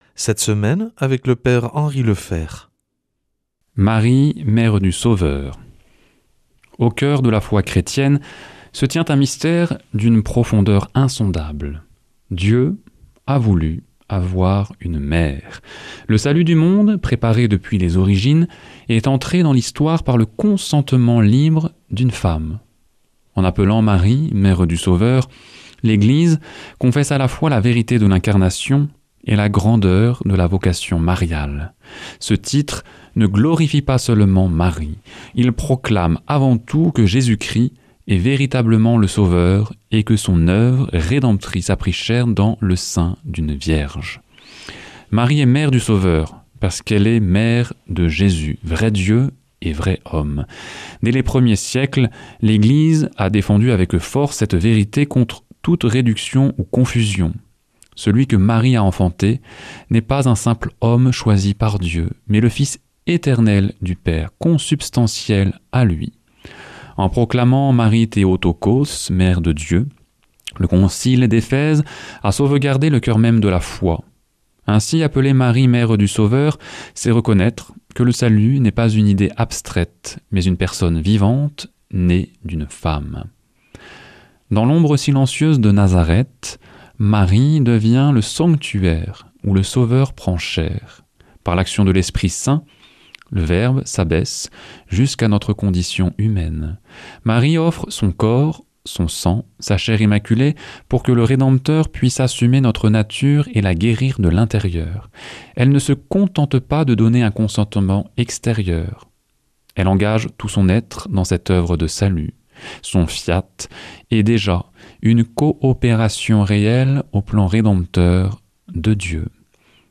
jeudi 19 février 2026 Enseignement Marial Durée 10 min